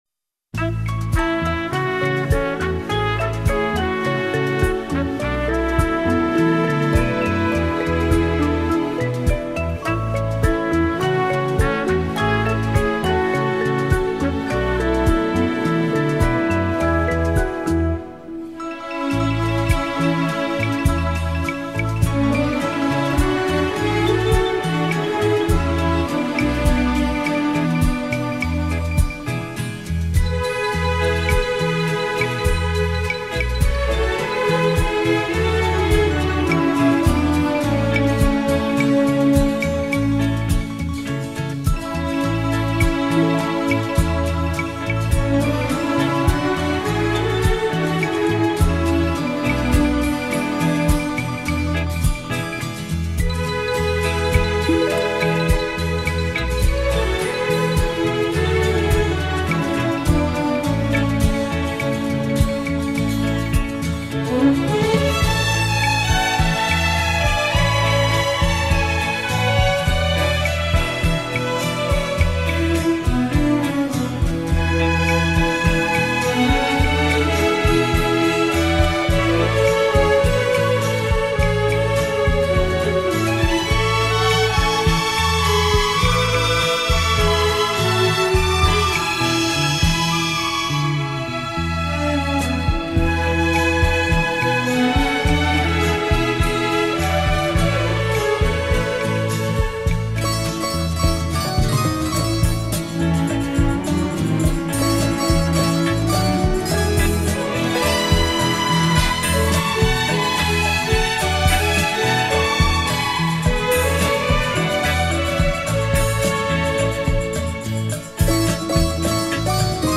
Здесь нет бэк-вокала, а там была женская группа.